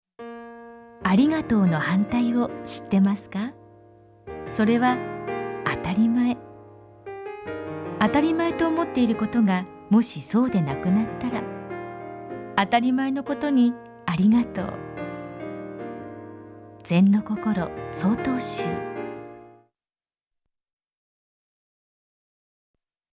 ラジオ放送コマーシャル（mp3ファイル）